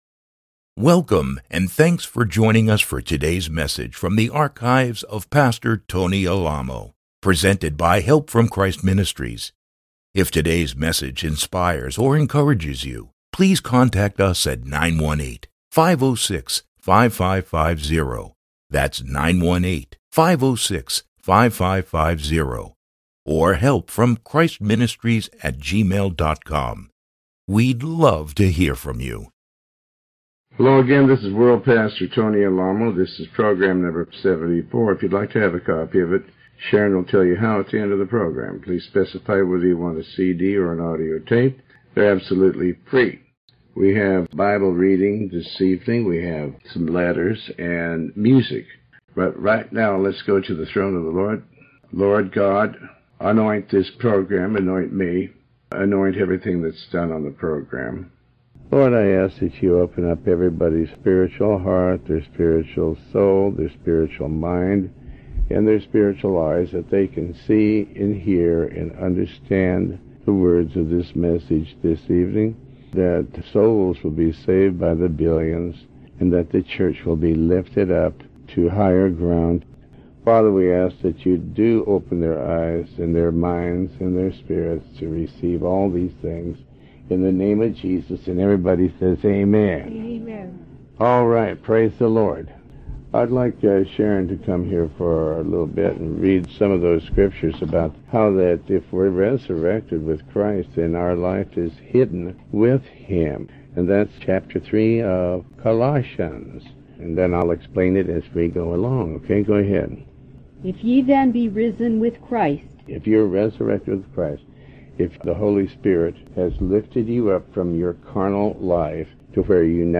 Sermon 74A